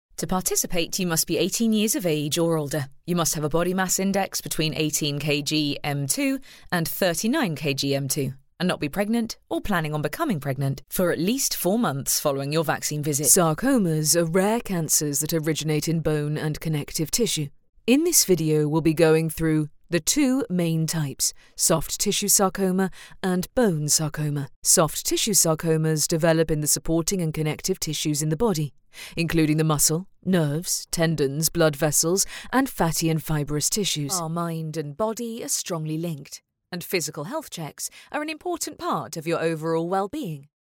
Female
English (British)
My natural vocal tone is sincere, friendly and direct with a clarity and warmth.
Medical Narrations